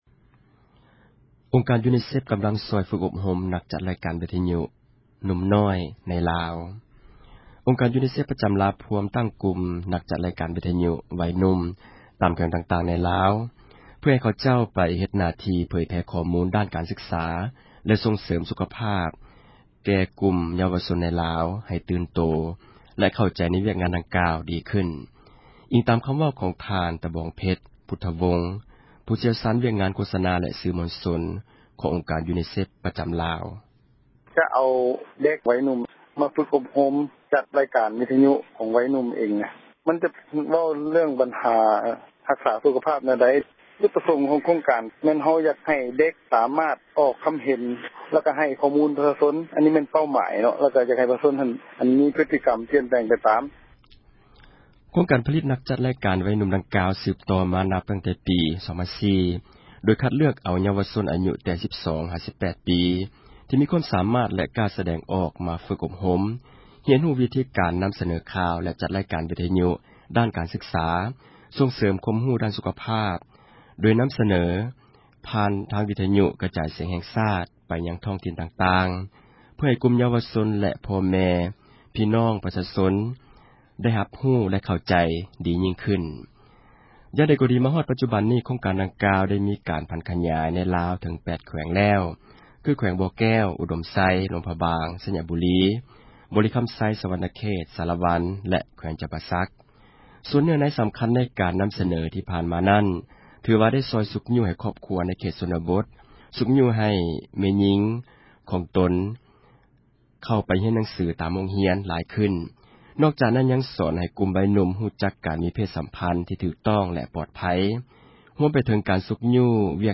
ສຽງຢູນີແຊຟ໌ວິທະຍຸ